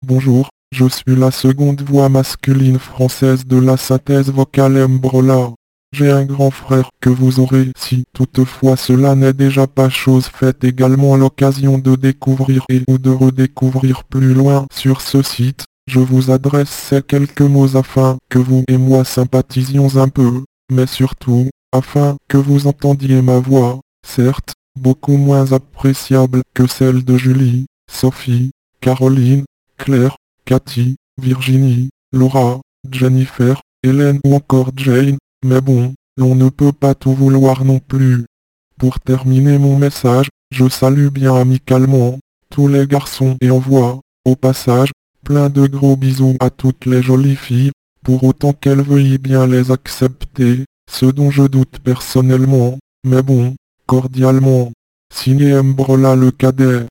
Texte de démonstration lu par MBrola, le cadet (Homme; français)
Écouter la démonstration de MBrola, le cadet (Homme; français)